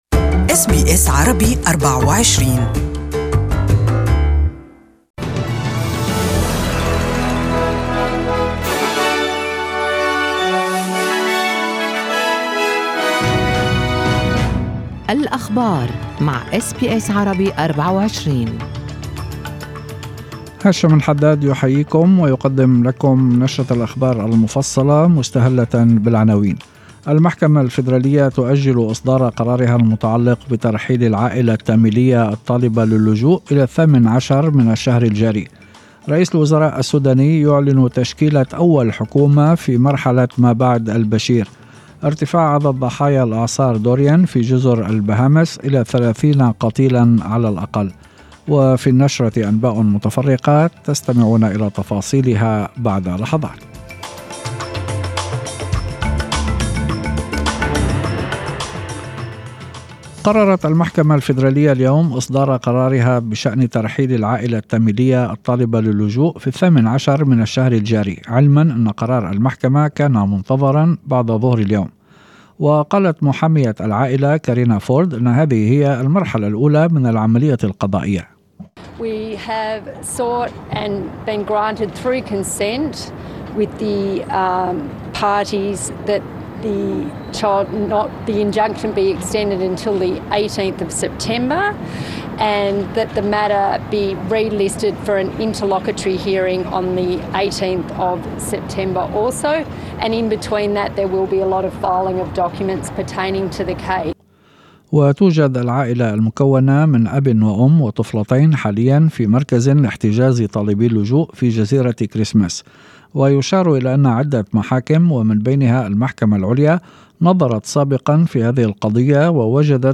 Evening News: Biloela Tamil family to stay in Australia for at least another 12 days after case delayed